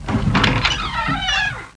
scary
1 channel